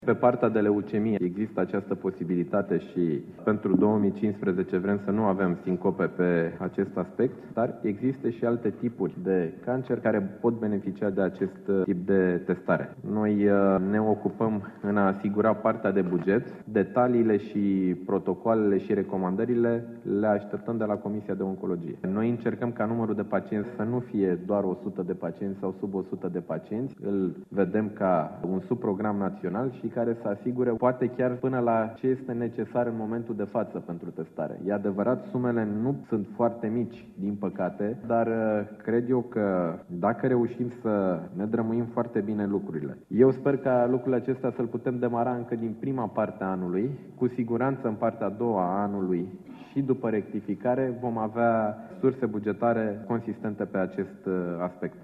Ministrul sănătăţii, Nicolae Bănicioiu a menţionat că anul acesta vor exista fonduri pentru programul de depistare precoce a cancerului de col uterin, si că va fi introdusă testarea genetică pentru bolnavii de cancer: